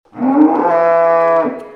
jumpscare.wav